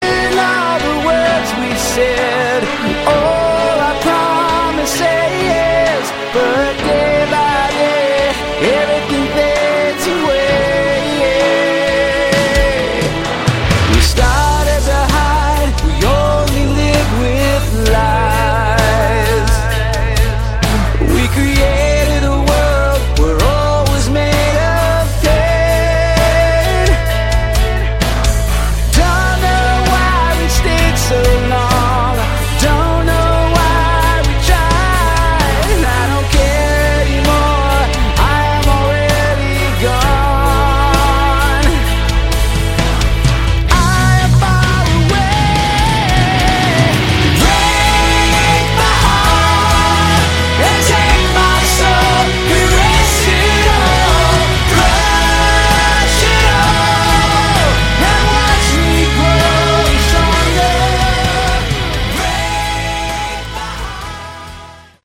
Category: Melodic Metal
drums
lead vocals on track 4